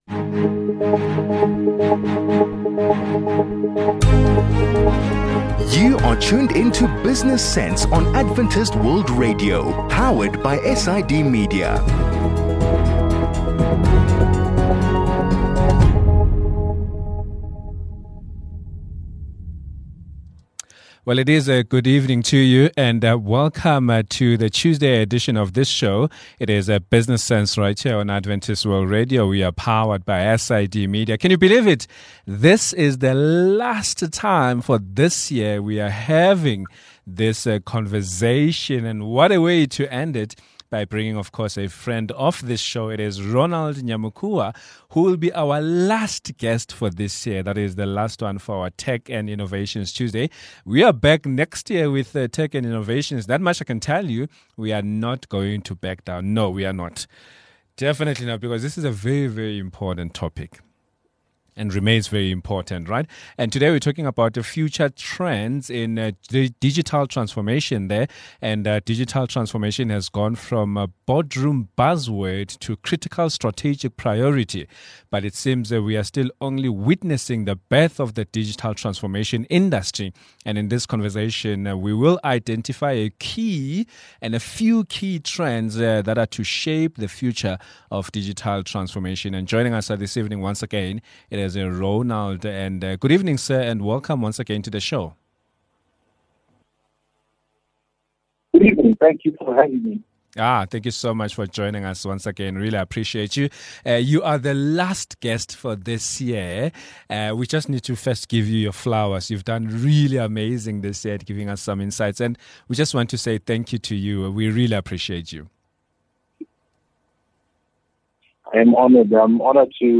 In this conversation, we will identify a few key trends that are set to shape the future of digital transformation.